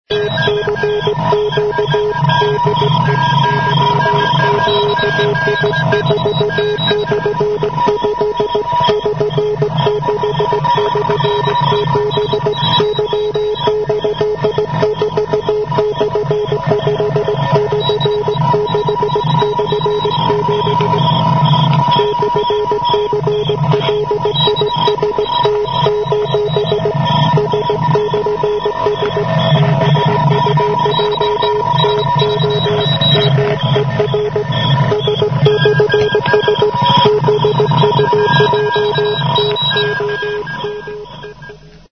Frequencies Morse Telegraphy: